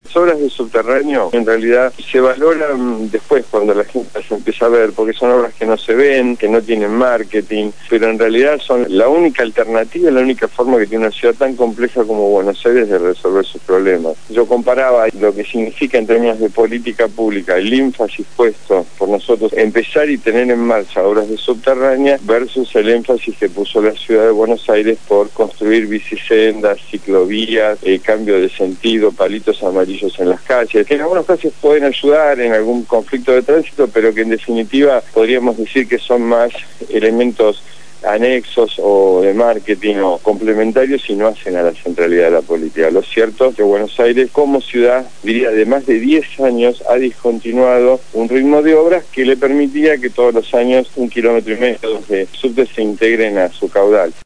Lo dijo Juán Pablo Schiavi, secretario de Transporte de la Nación, en el programa «Punto de partida» (Lunes a viernes de 7 a 9 de la mañana), por Radio Gráfica FM 89.3